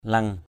/lʌŋ/ (d.) mồng tơi = Basella rubra. njam leng W’ l$ rau mồng tơi. habai njam leng h=b W’ l$ nấu canh rau mồng tơi.